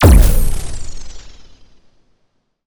sci-fi_shield_power_deflect_boom_02.wav